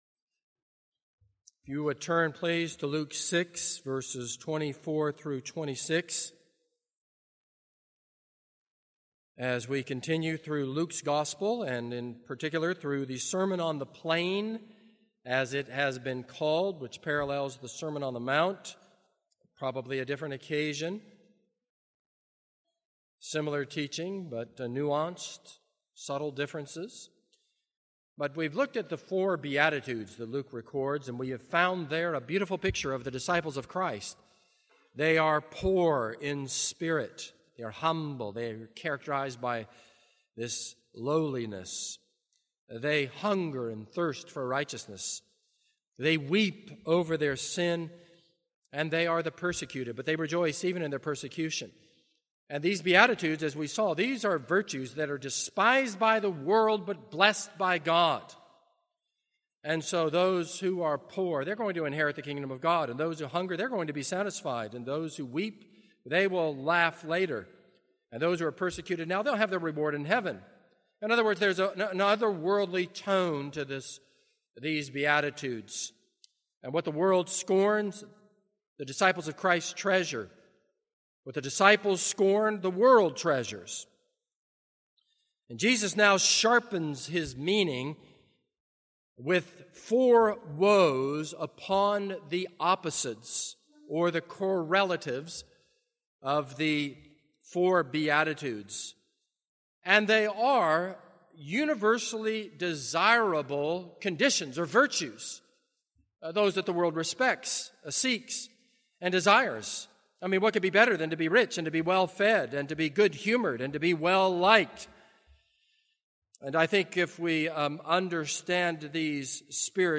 This is a sermon on Luke 6:24-26.